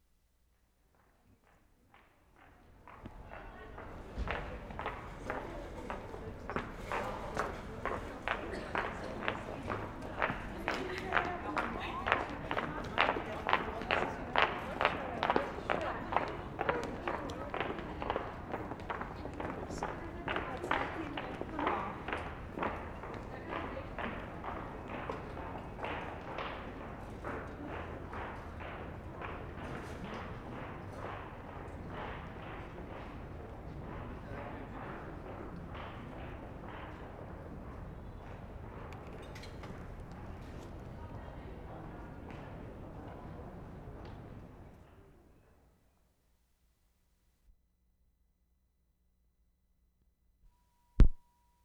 Stockholm, Sweden Feb. 9/75
MORE CLOGS ON PAVEMENT
3&4. Girls walking across a street (no traffic).